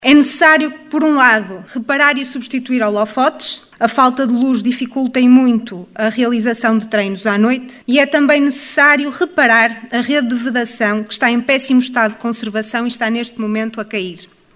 O assunto foi levado à Assembleia no período destinado às intervenções do público pela voz de uma delegada da assembleia de freguesia de Lanhelas, Joana Whyte, eleita pela coligação OCP.